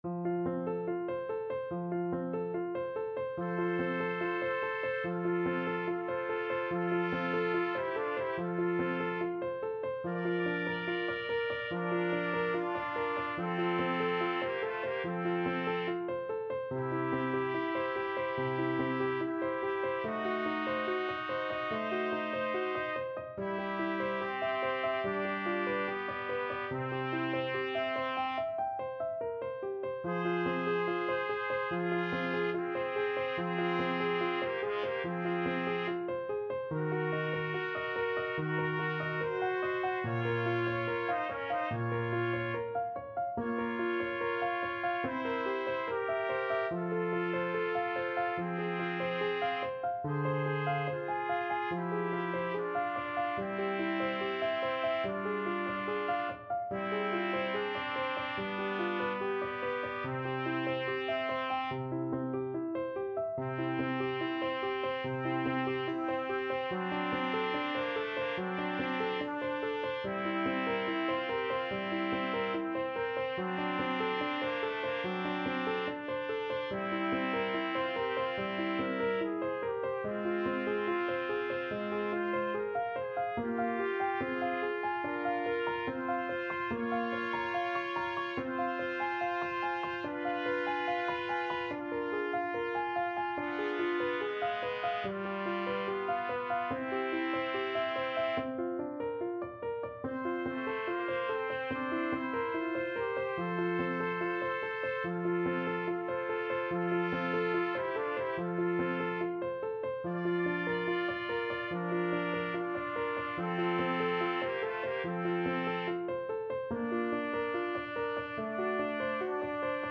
4/4 (View more 4/4 Music)
Andante =72
Classical (View more Classical Trumpet Duet Music)